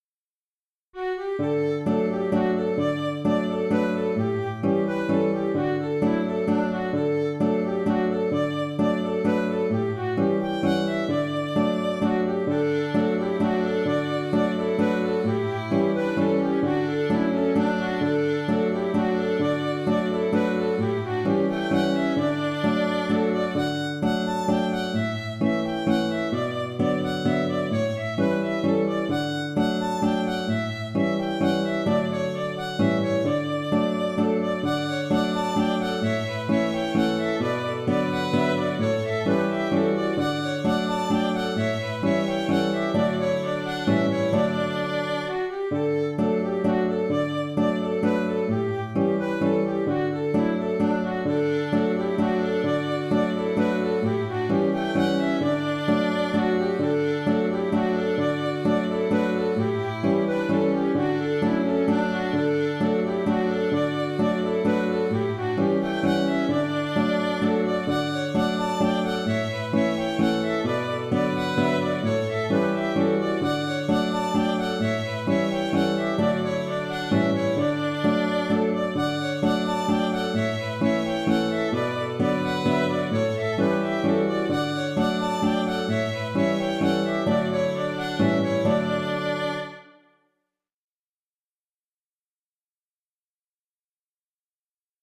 Auteur : Trad. Irlande.
Mazurka